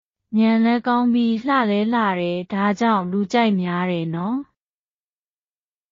ミャンレカウンビー　ラーレラーレ　ダーチャウン　ルーチャイミャーデーノ
当記事で使用された音声（日本語およびミャンマー語）はGoogle翻訳　および　Microsoft Translatorから引用しております。